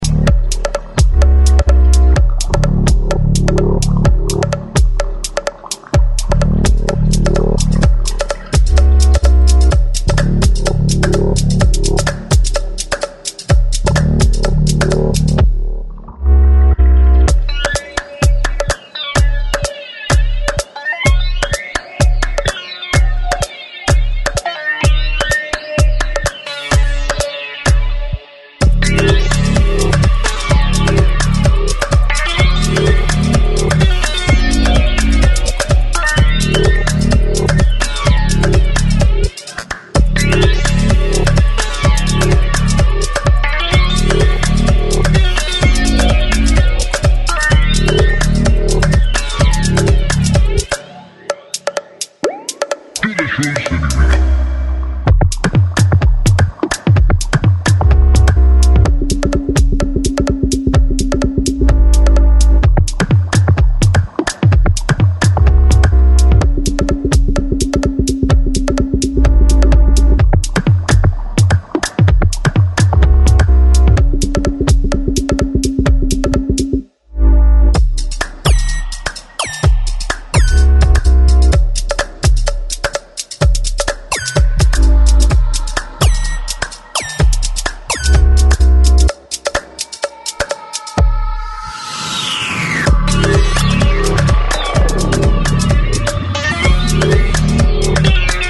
Electronix Bass Ambient Breaks Experimental